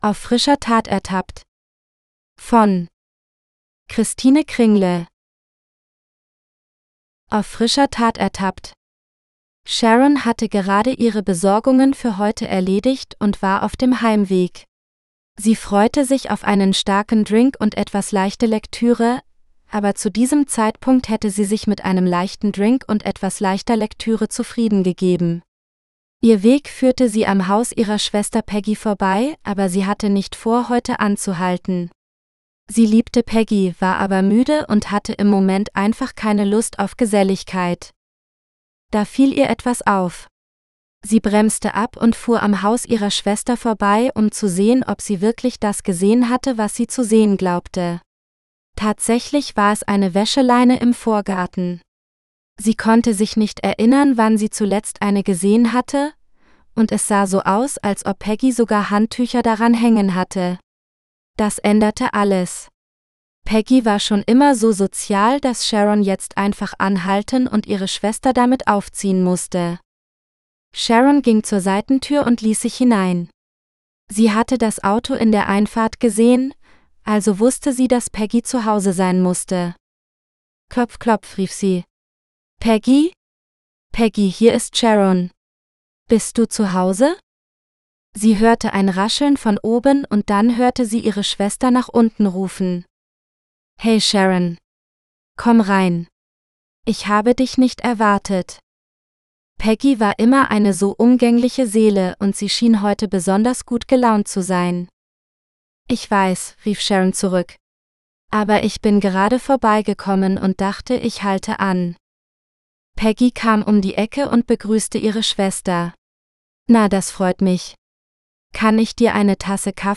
Auf frischer Tat ertappt (Caught In The Act) (AUDIOBOOK – female): $US2.99